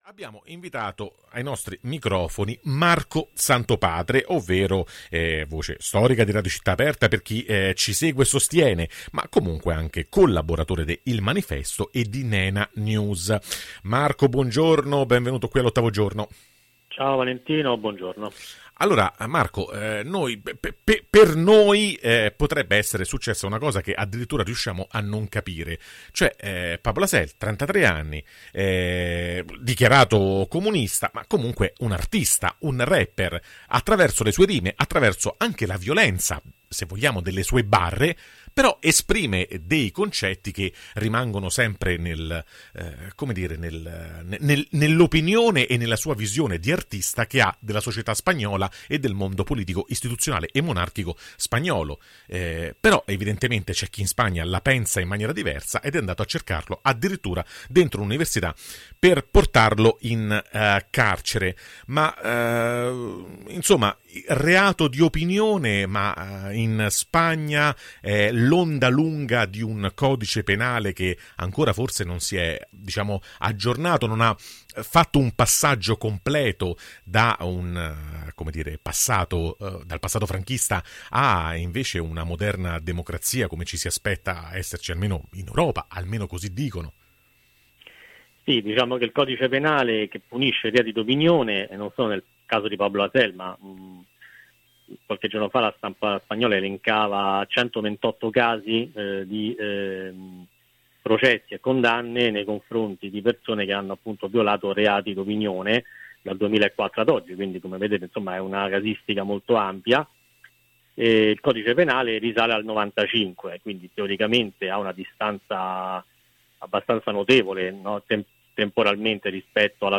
Davvero in Spagna un rapper viene incarcerato per i suoi testi? [Intervista